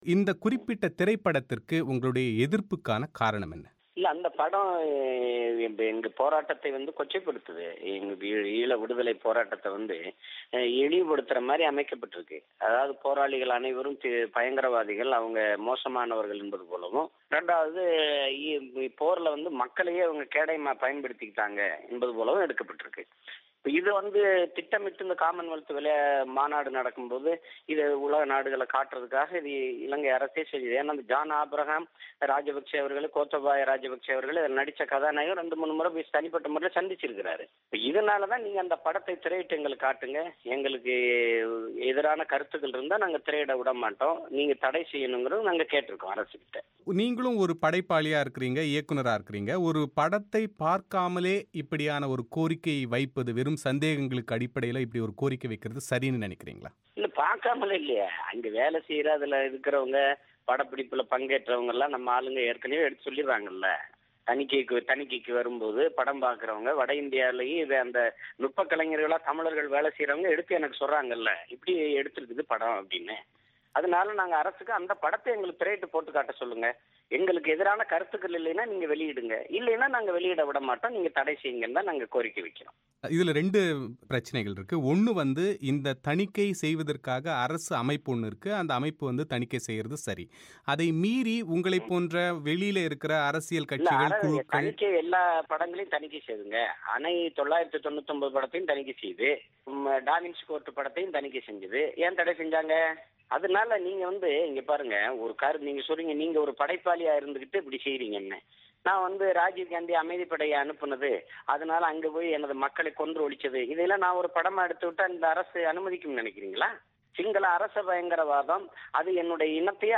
இலங்கை இனமோதலை கதைக்களமாக கொண்டு எடுக்கப்பட்டிருக்கும் ஹிந்தி திரைப்படமான மெட்ராஸ் கபே என்கிற திரைப்படத்தை நாம் தமிழர் கட்சி எதிர்ப்பது ஏன் என்பது குறித்து அந்த கட்சியின் தலைவர் சீமான் பிபிசி தமிழோசைக்கு அளித்த பிரத்யேக செவ்வி